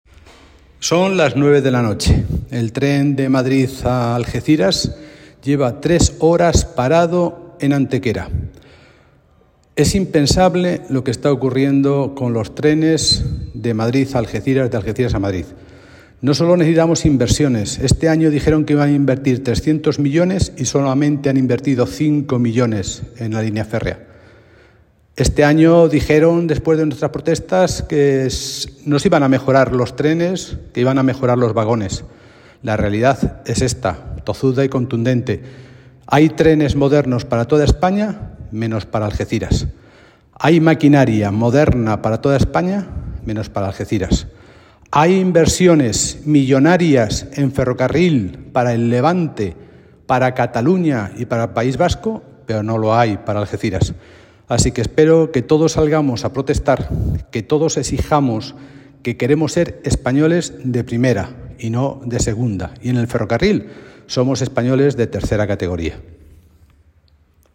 José Ignacio Landaluce, ha realizado unas declaraciones mostrando su indignación por lo sucedido, calificando de  impensable lo que sigue sucediendo con los trenes entre Algeciras y Madrid.